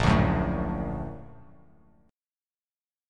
Bb+M7 chord